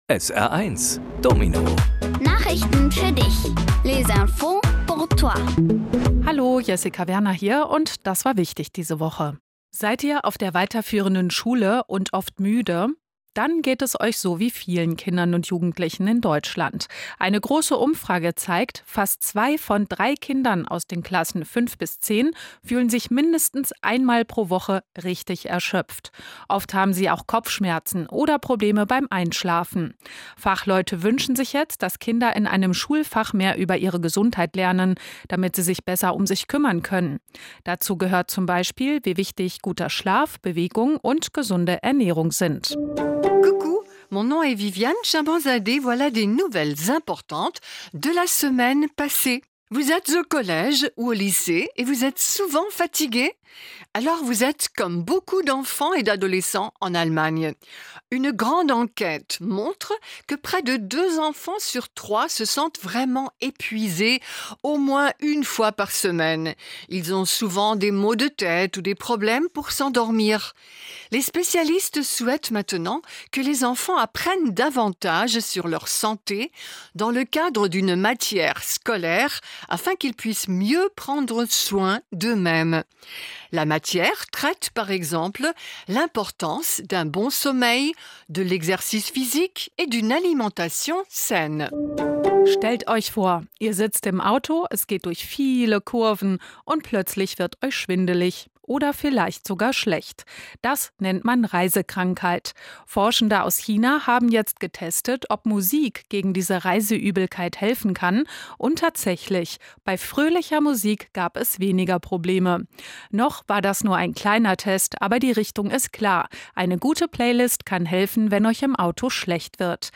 Kindgerechte Nachrichten auf Deutsch und Französisch: Erschöpfung bei Kindern und Jugendlichen, fröhliche Musik gegen Reiseübelkeit, Nord- und Ostsee sind sehr warm und Erdbeben in Afghanistan